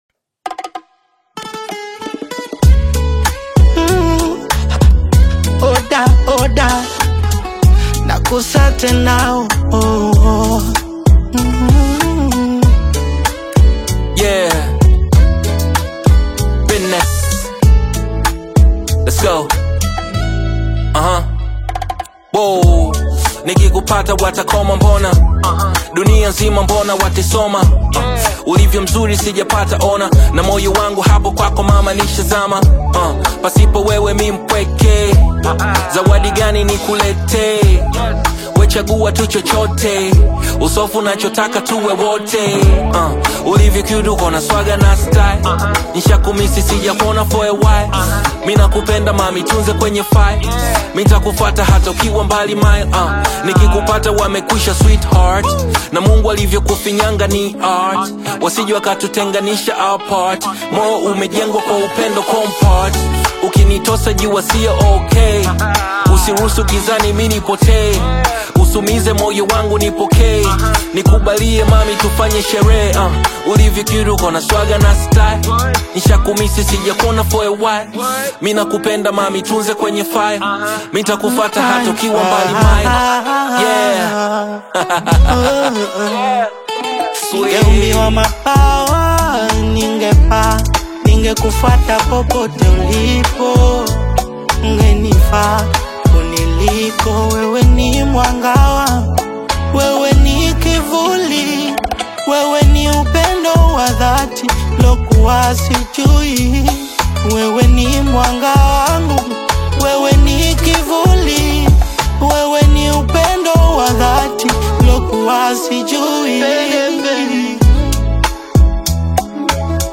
a renowned figure in the vibrant Bongo Flava scene
smooth, soulful vocals